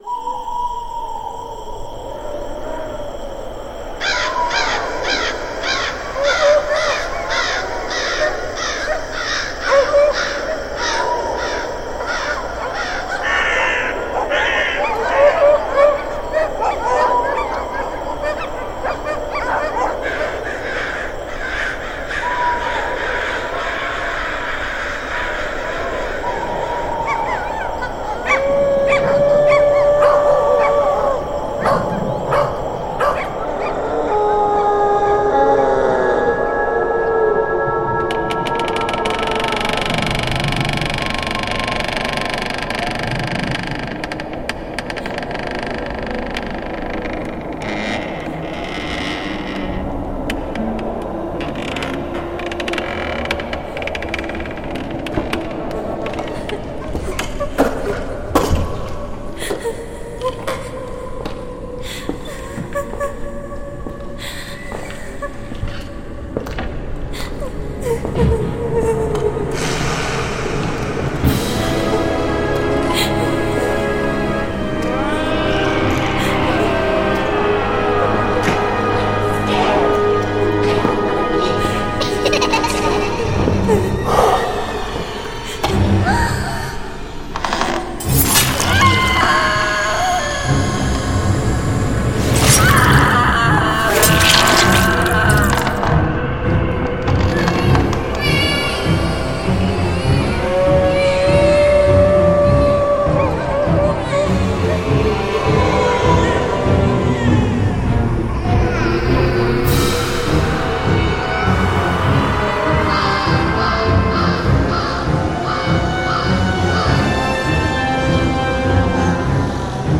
如：令人不安和怪异的气氛、可怕的环境、怪异和超自然的声音
令人不安的骨头粉碎和血腥声音、血液爆炸、血液溢出和内脏飞溅
血块撕裂、黑暗的无人机、怪物咆哮、食肉僵尸和邪恶生物的声音
令人毛骨悚然的男性和女性尖叫、咆哮的野兽咆哮、恶魔般的小丑声音、可怕的幽灵嚎叫
哭泣的狼、恶魔般的笑声、鬼的低语、不祥的猫头鹰和其他夜鸟的声音
令人毛骨悚然的教堂钟声、嚎叫的风、闪电和雷声、嘎嘎作响的锁链、阴险的笑容、吱吱作响的大厅门